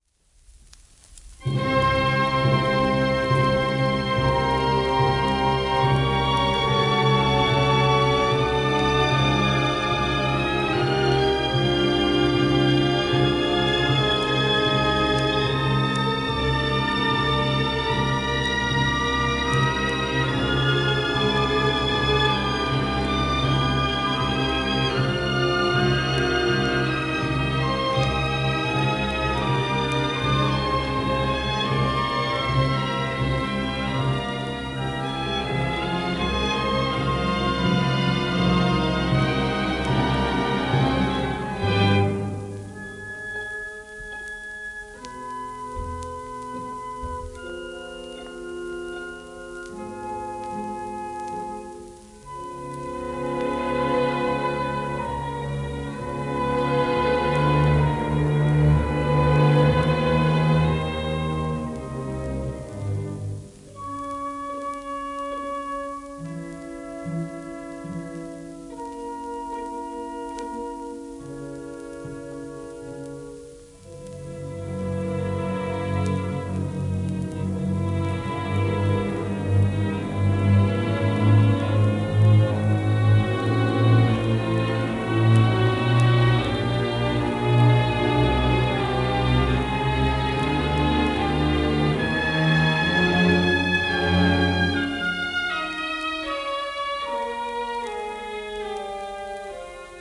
1937年、ウィーン楽友協会ホールでの録音